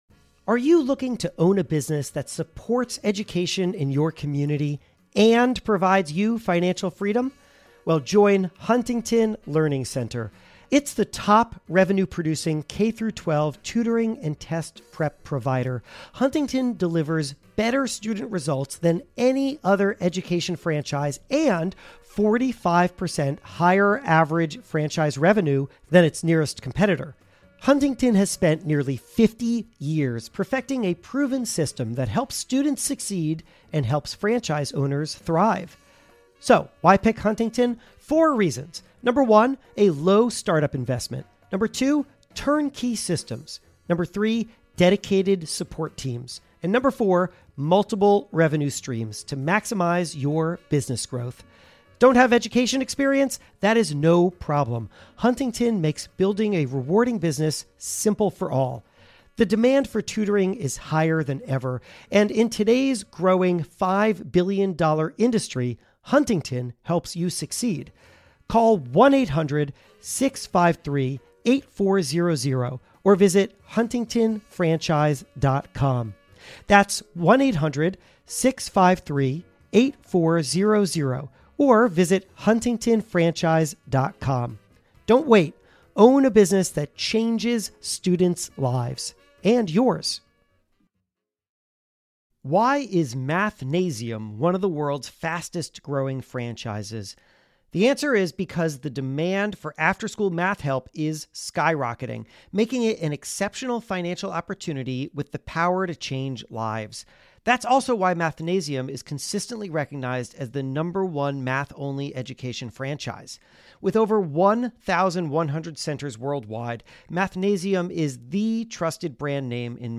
In 2006, a debate raged inside of Nintendo: Do they bundle their new Wii console with the game Wii Sports, or sell the game separately? Reggie Fils- Aimé was president of North America at the time, and he argued yes — because even though it meant giving up revenue upfront, it would lead to more Wii sales later. Here, he explains the battle and how he won it.